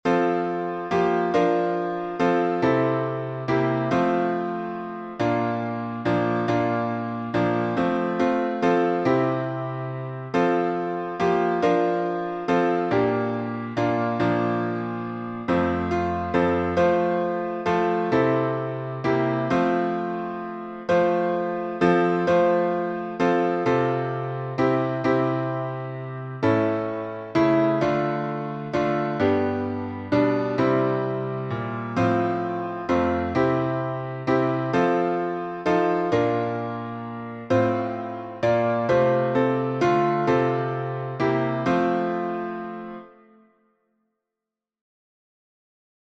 #4081: Trusting Jesus — F major | Mobile Hymns